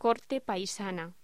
Locución: Corte paisana
voz